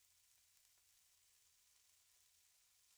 meatSizzle.wav